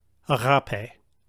Transliterated into English letters, it is spelled agapē.